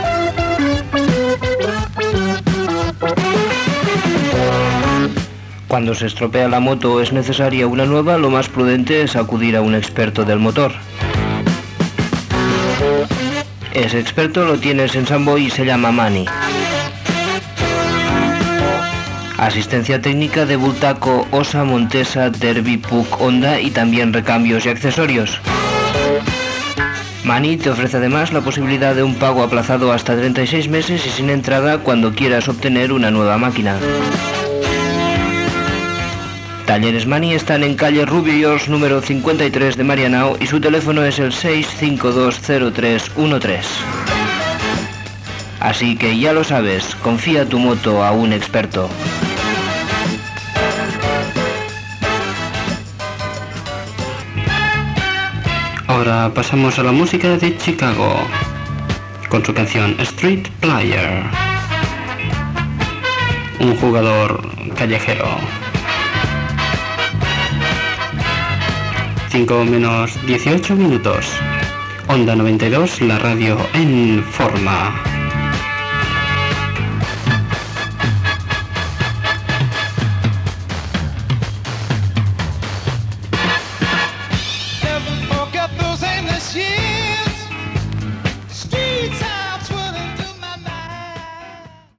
b710f72e3d037417fef13d533816fdea7d8ec11d.mp3 Títol Onda 92 Emissora Onda 92 Titularitat Tercer sector Tercer sector Musical Descripció Publicitat i tema musical amb identificació de l'emissora.